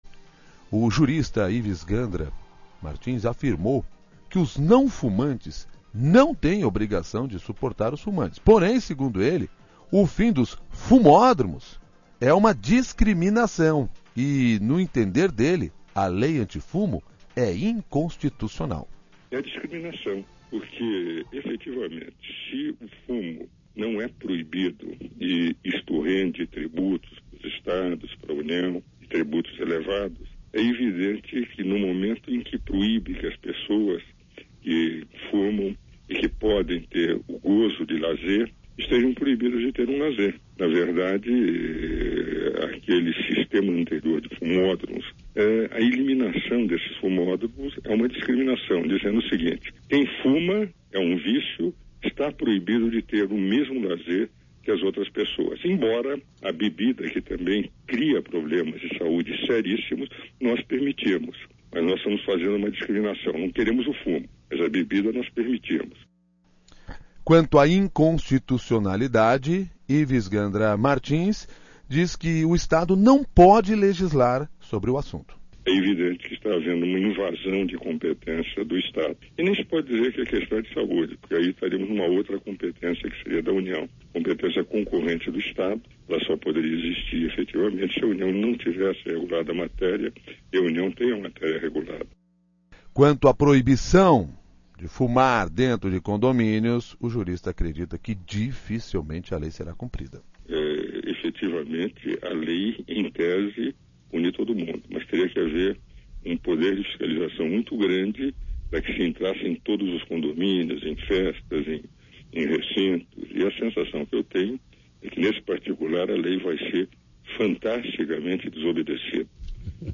Comece com o jurista Ives Gandra que é contra lei